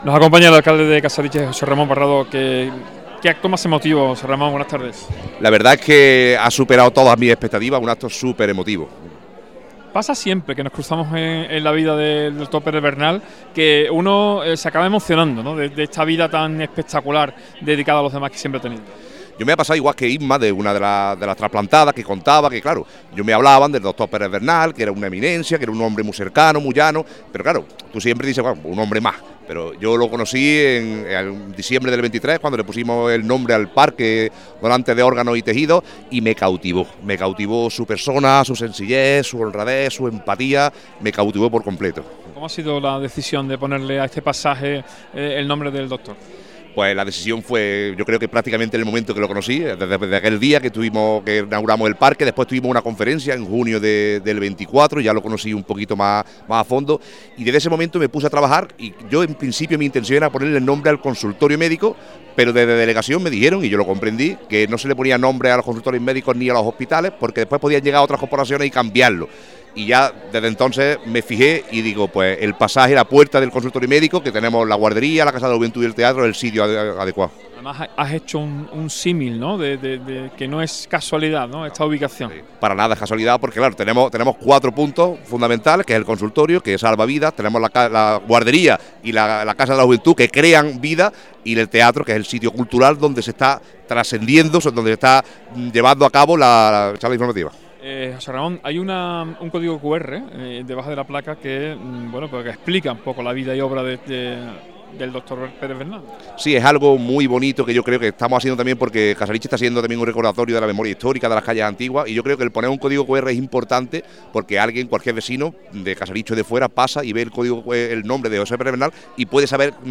ENTREVISTA JOSÉ RAMÓN PARRADO, ALCALDE CASARICHE